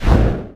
tbd-station-14/Resources/Audio/Voice/Talk/lizard.ogg at 57bf21cb6a5f00b51a41d1e74df9a164120efd93
Lizz and slime speech
lizard.ogg